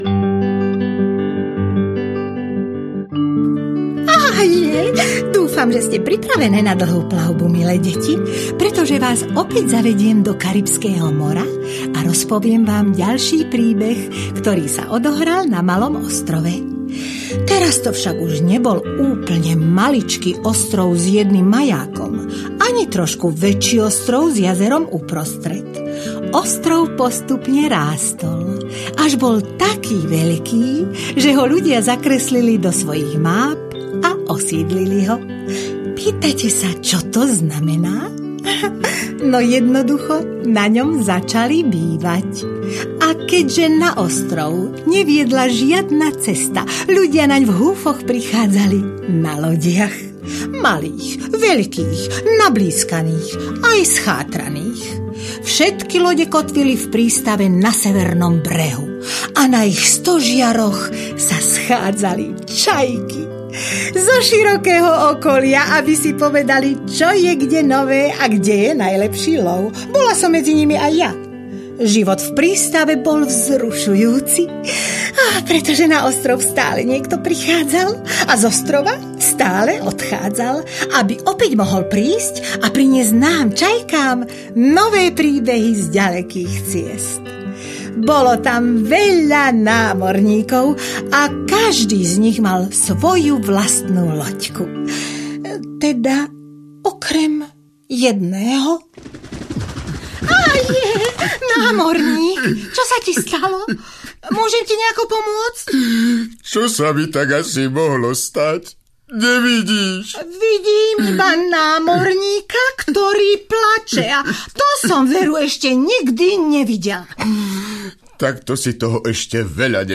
Rozprávky z malého ostrova audiokniha
Ukázka z knihy